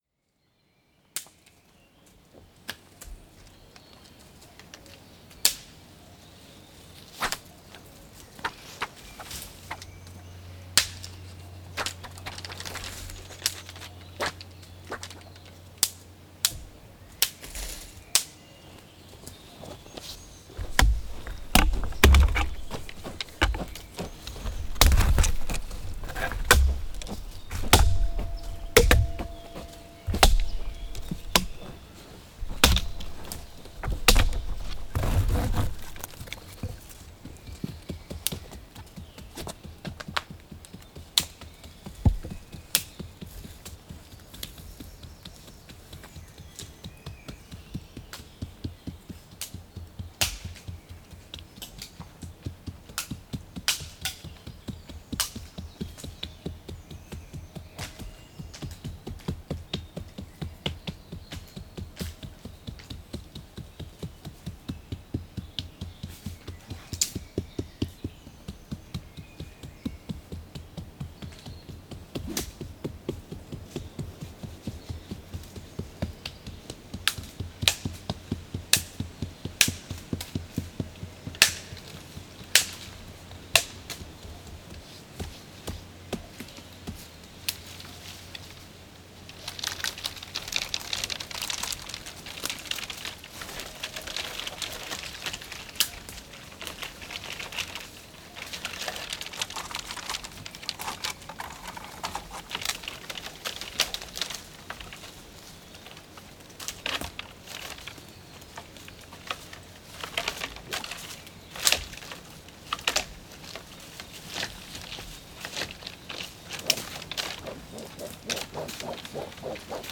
Manipulação de ramos de pinheiro em pinhal junto à estrada N337 em Boa Aldeia, Boa Aldeia a 29 Março 2016.
NODAR.00535 – Boa Aldeia, Farminhão e Torredeita: Manipulação de ramos de pinheiro em pinhal junto à estrada N337